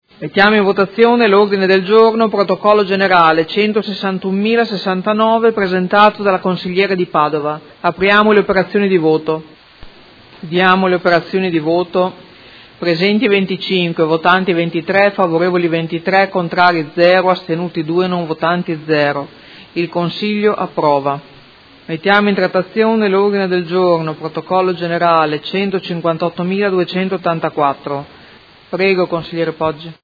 Seduta del 13/12/2018. Mette ai voti Ordine del Giorno presentato dai Consiglieri Di Padova, Lenzini, Liotti, Forghieri, De Lillo e Arletti (PD) avente per oggetto: Parma capitale italiana della cultura 2020, quale ruolo per Modena?